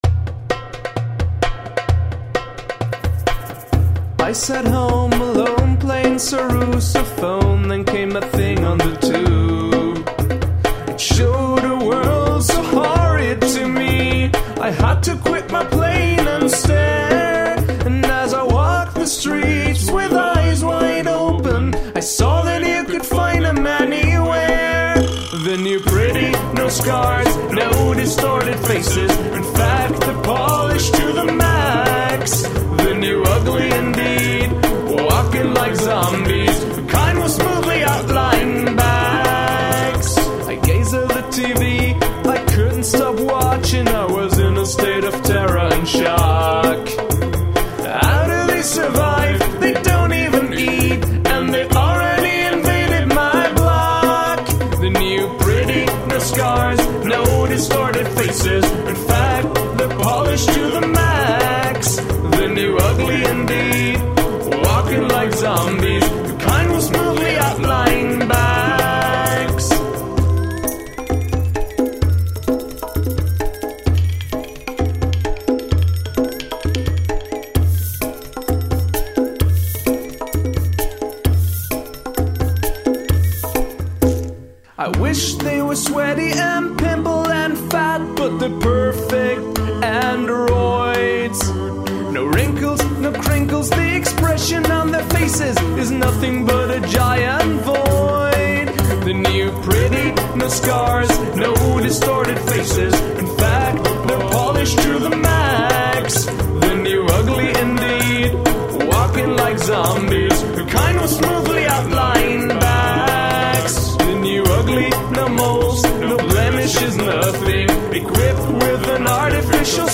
Only Percussion and Voice (no pianos allowed)
vocals, darbouka, djembe, bongos, ibo drum,
and toy xylophone (barely audible, during the last verse).